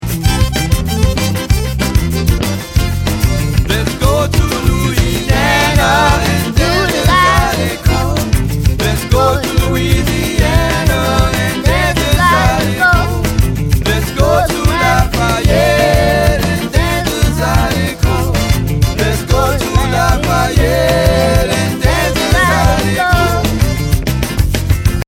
His Zydeco Family & Friends